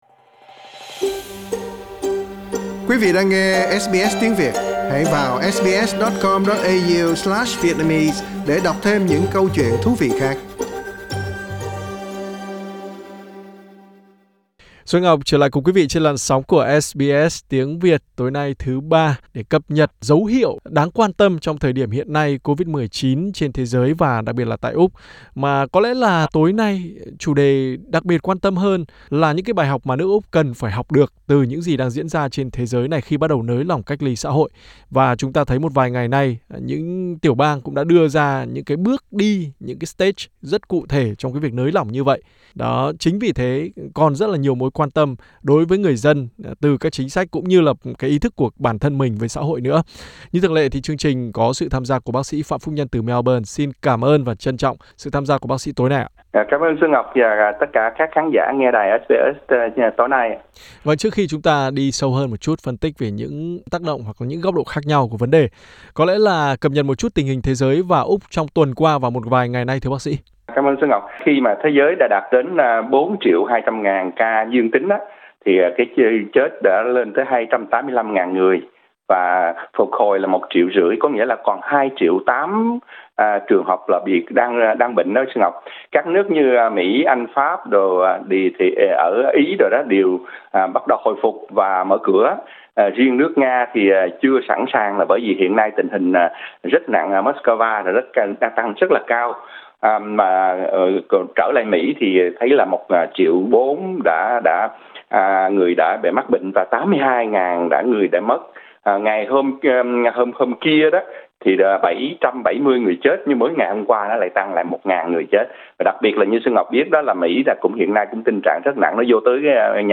SBS Việt ngữ